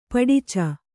♪ paḍica